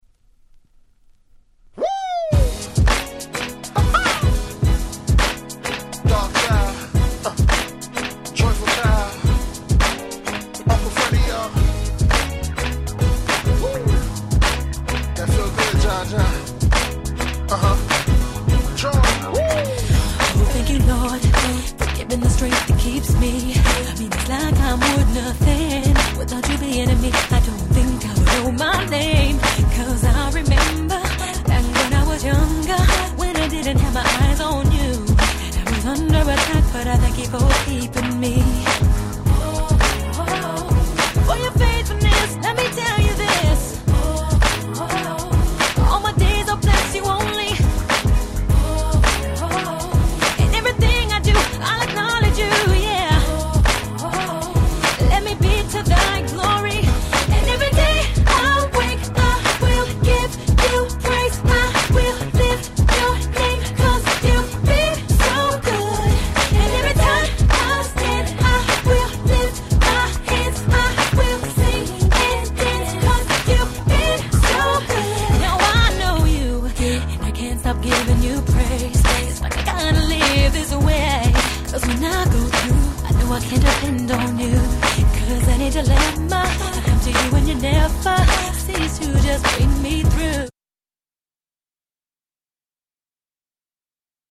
キャッチー系